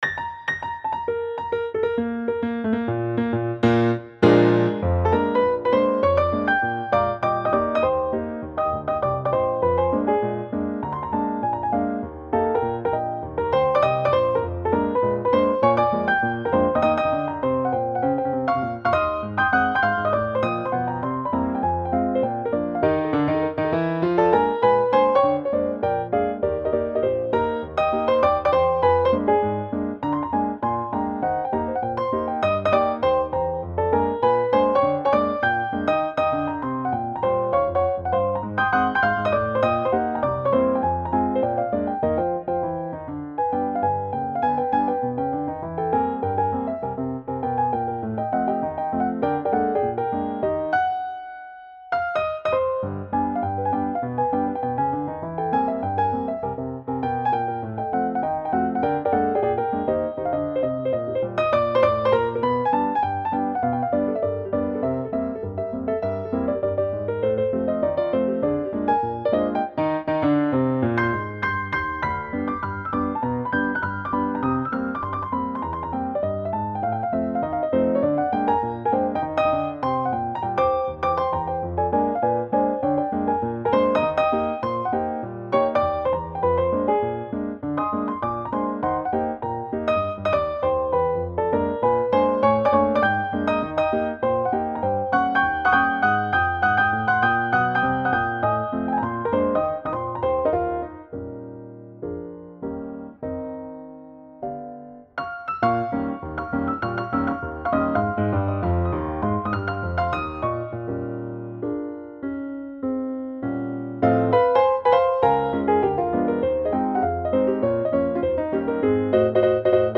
Stride piano